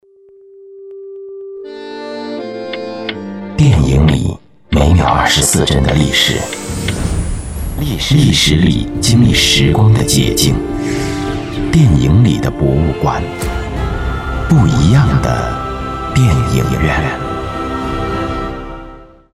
配音老师
男85号